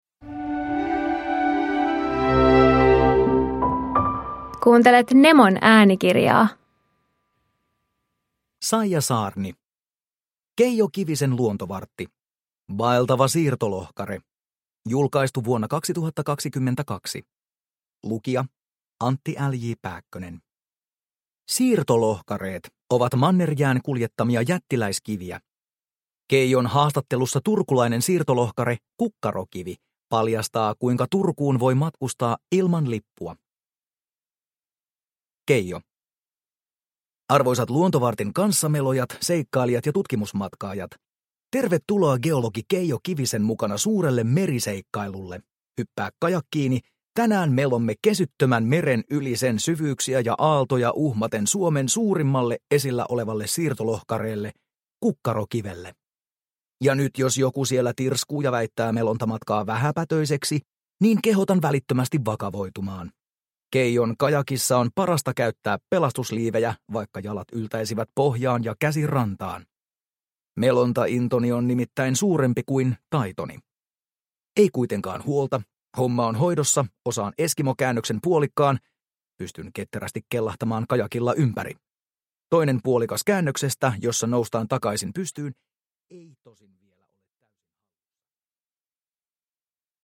Vaeltava siirtolohkare – Ljudbok – Laddas ner